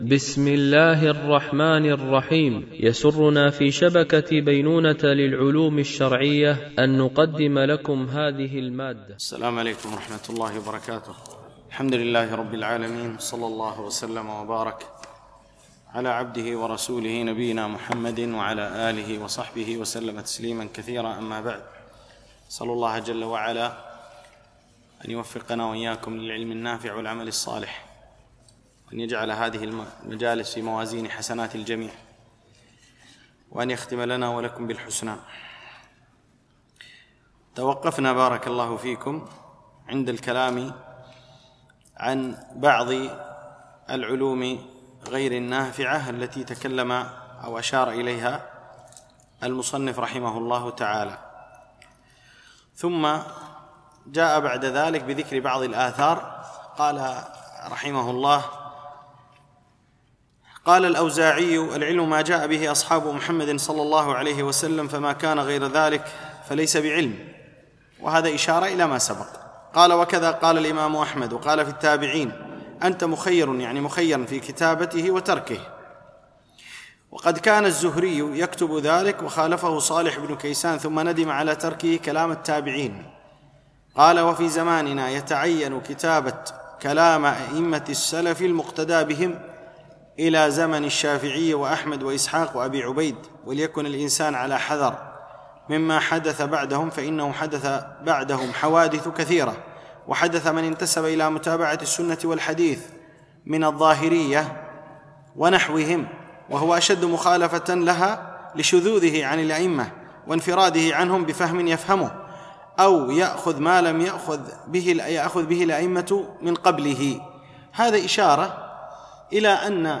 الدورة العلمية الثانية المترجمة للغة الإنجليزية، لمجموعة من المشايخ، بمسجد أم المؤمنين عائشة رضي الله عنها